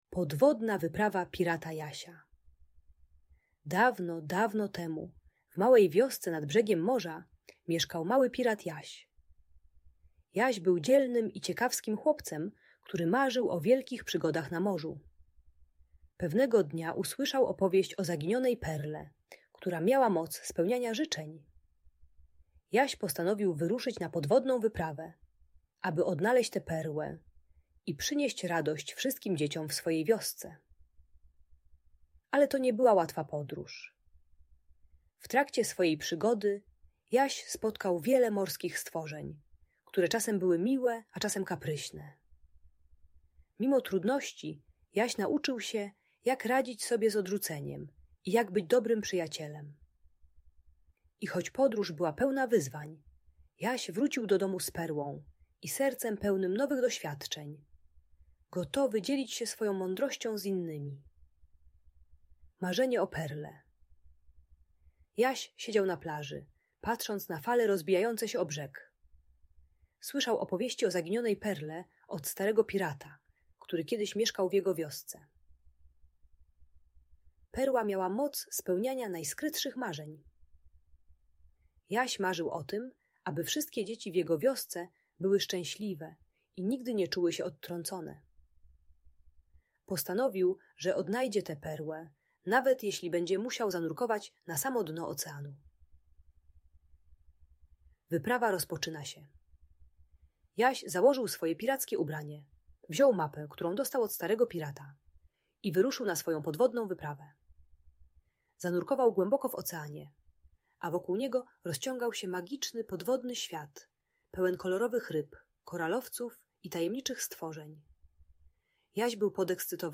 Podwodna Wyprawa Pirata Jasia - Audiobajka